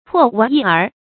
破玩意儿 pò wán yì ér 成语解释 低劣的技艺；低劣的对象。